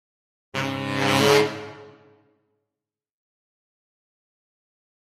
Saxophone
Horn Section Criminal Increasing 4 Lower Type B